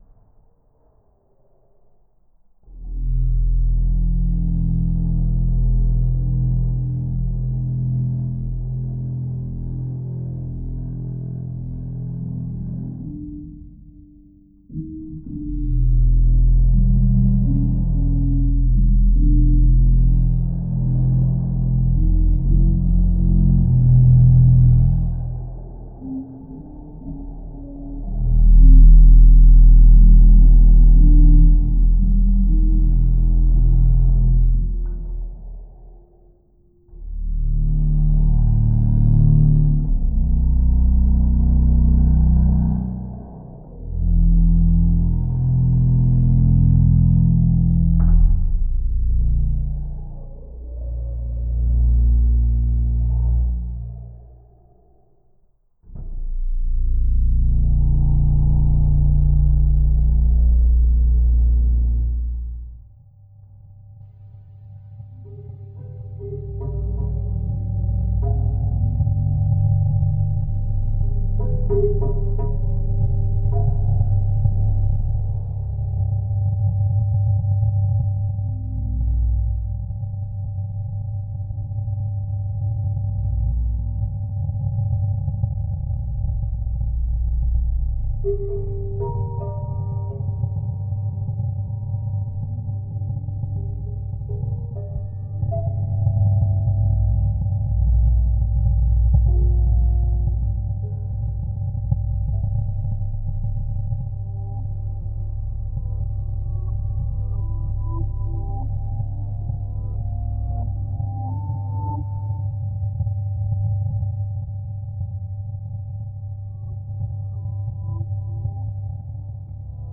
proches de la musique concrète, électroacoustique et électronique
Cette courte pièce est constituée d'un piano, d'une flûte basse et d'un vinyle sur lequel est gravé… du silence. Les sons sont saturés, presque fanés, poussiéreux.